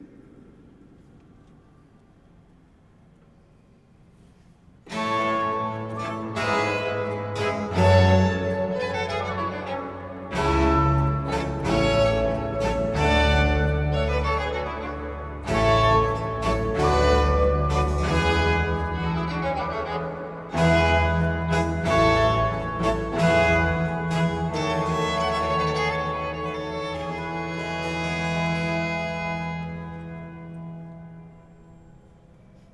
First modern performance
Live: 14/08/2019 Chiesa Collegiata di Arco (TN – Italy)